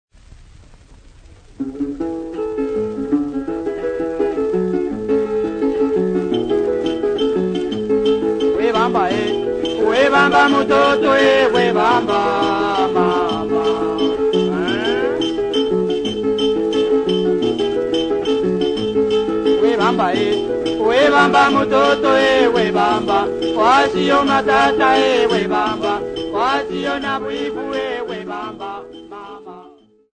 Popular music--Africa
Dance music
Dance music--Caribbean Area
Field recordings
Africa Democratic Republic of Congo City Not Specified f-sa
sound recording-musical